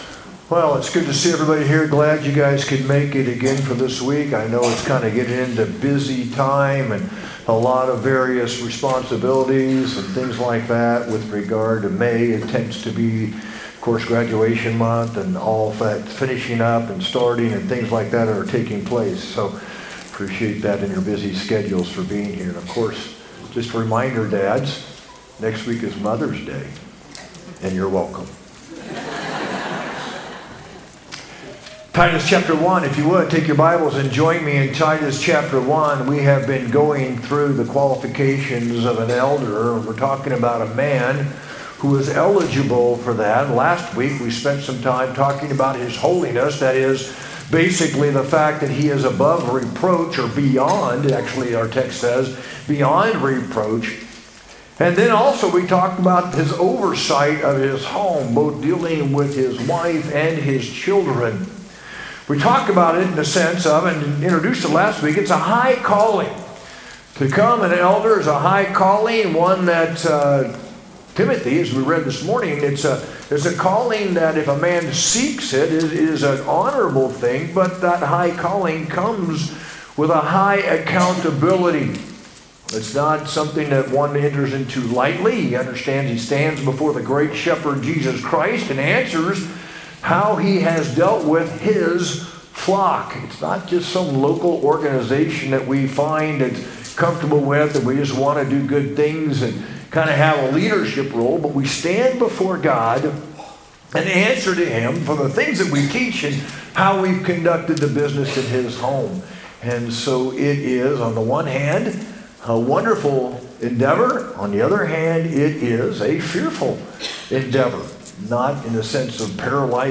sermon-5-4-25.mp3